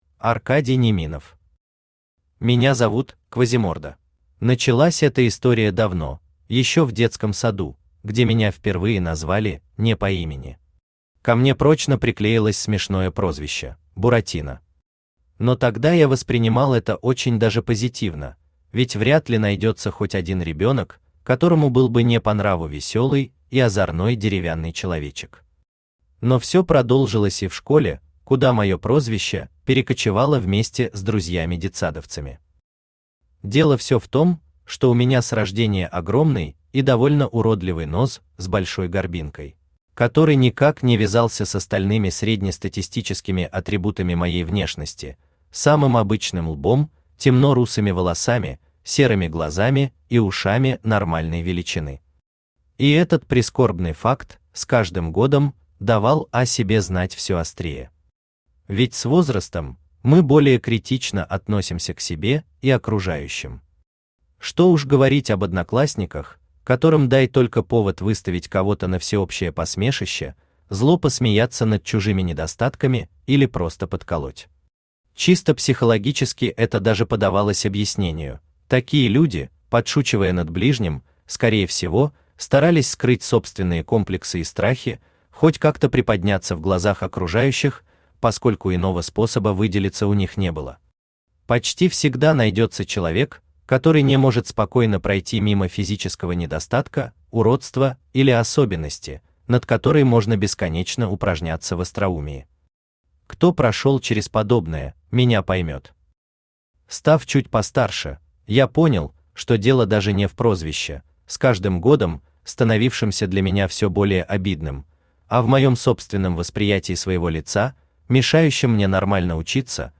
Аудиокнига Меня зовут Квазиморда | Библиотека аудиокниг